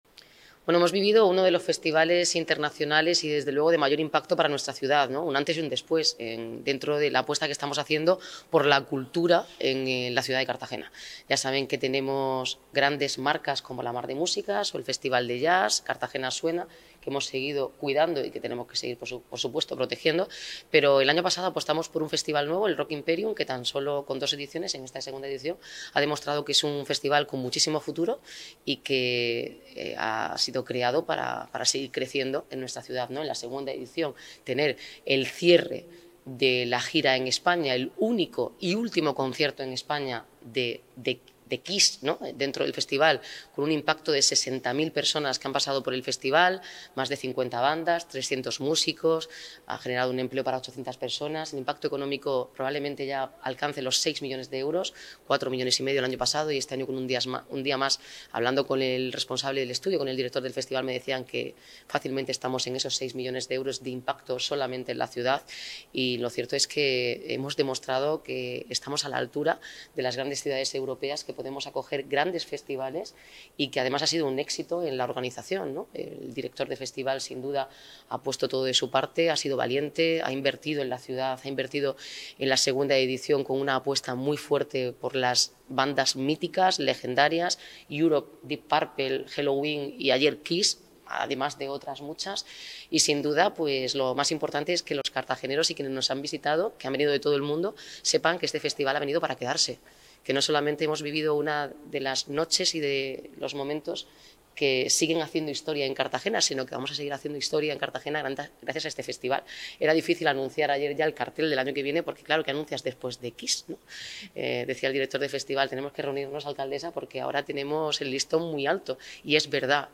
Enlace a Declaraciones de la alcaldesa de Cartagena sobre el balance del Rock Imperium